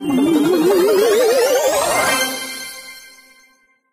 brawl_reroll_01.ogg